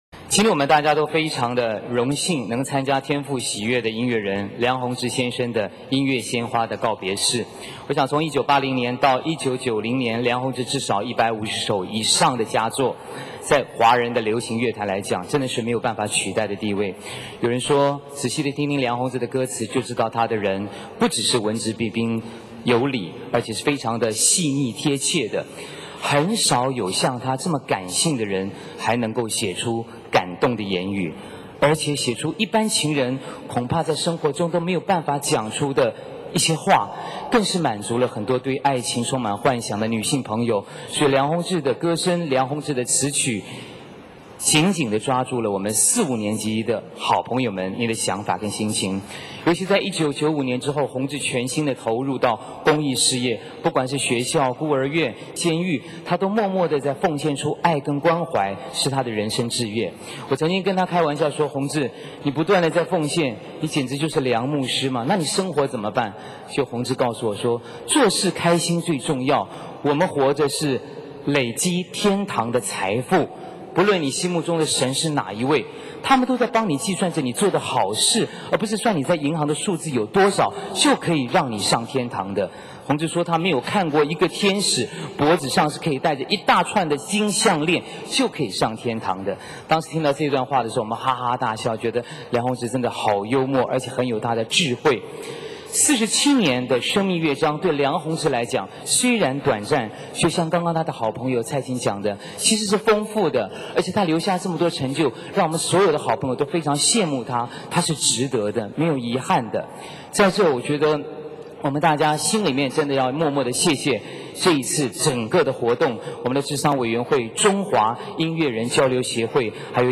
告别式由陈凯伦主持，首先介绍梁弘志的生平，从1980到1990年创作了150首词曲。